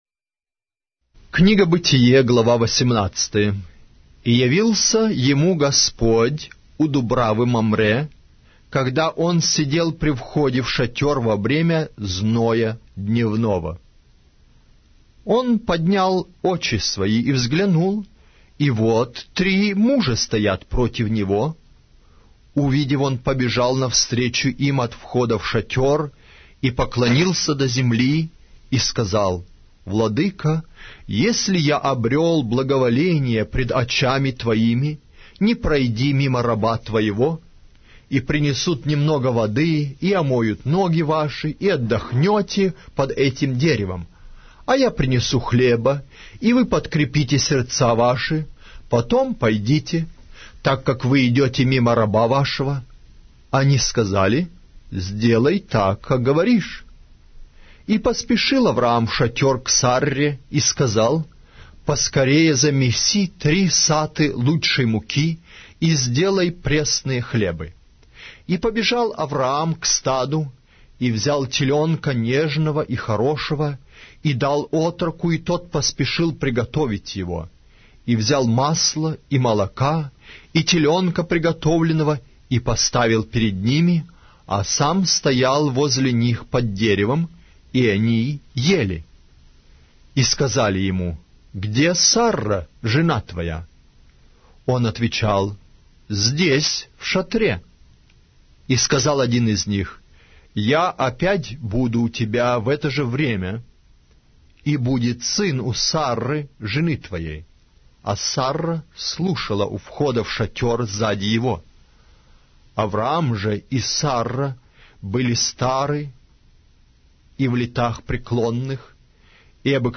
Аудиокнига: Книга 1-я Моисея. Бытие